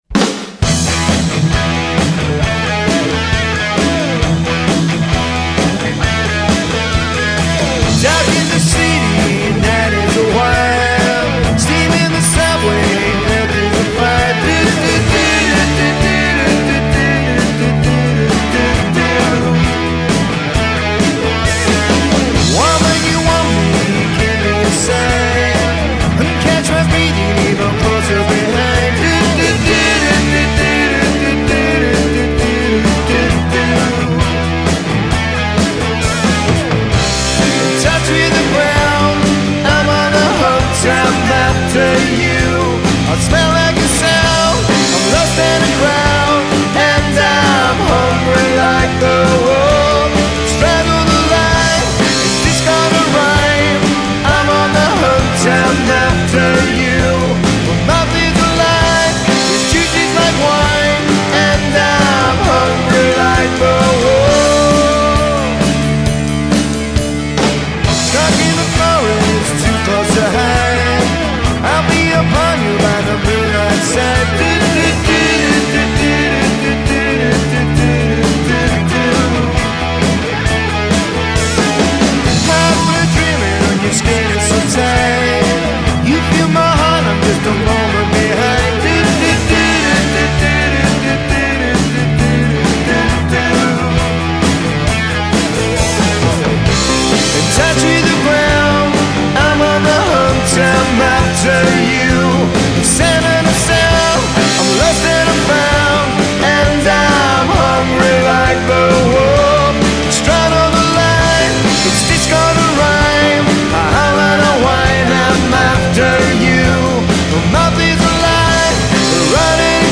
Classic Rock and Soul